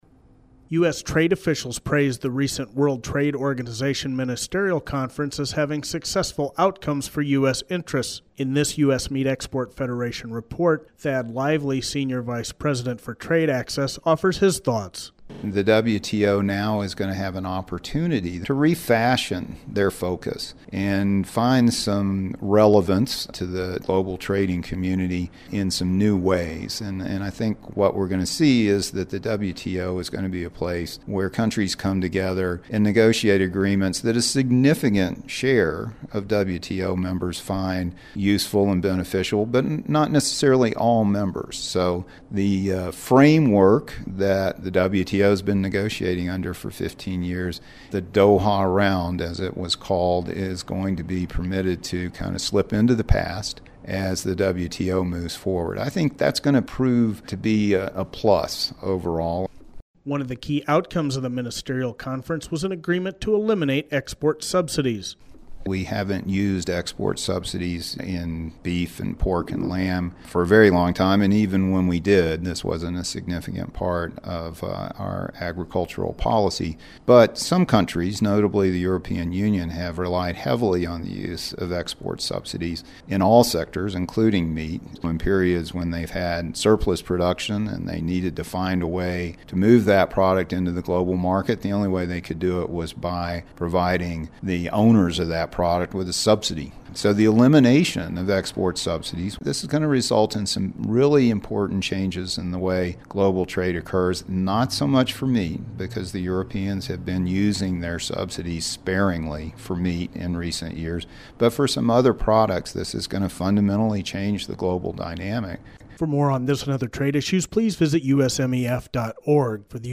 In the attached audio report